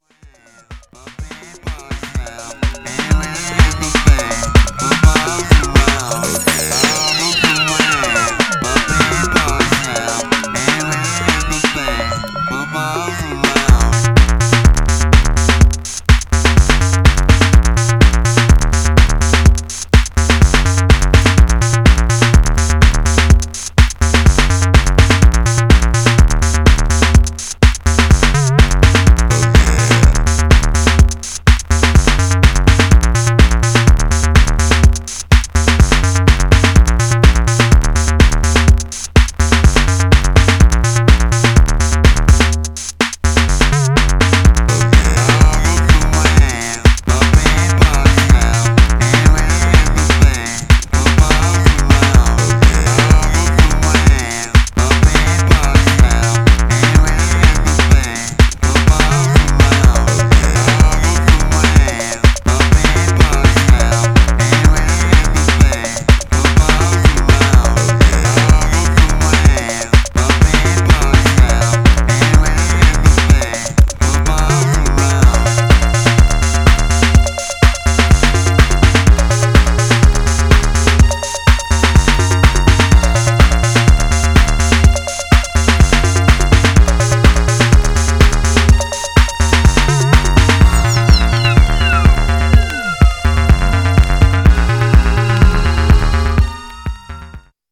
Styl: Electro, Breaks/Breakbeat